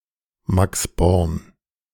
Max Born (German: [ˈmaks ˈbɔʁn]